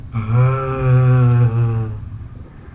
计算机 " 在笔记本电脑键盘上输入
描述：按下笔记本电脑键盘上的“Enter”按钮。